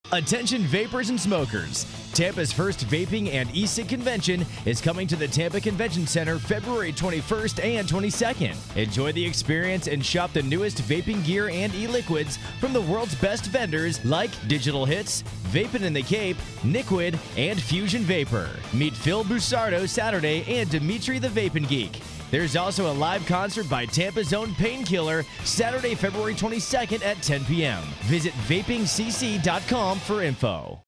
WHEN COMMERCIAL READS GO WRONG!